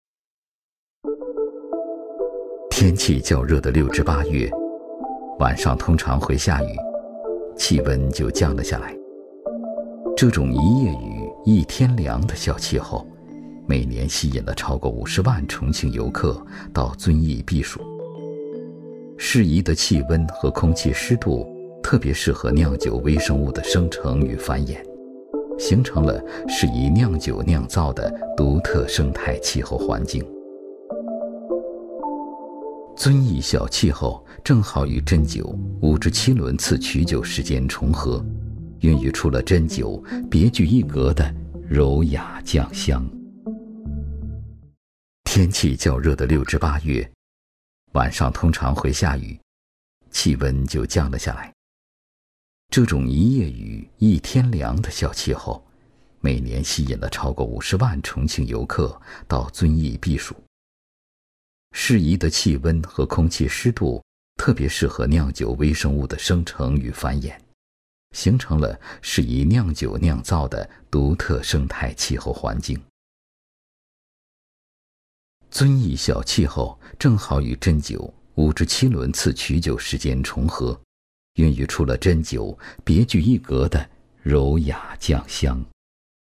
男29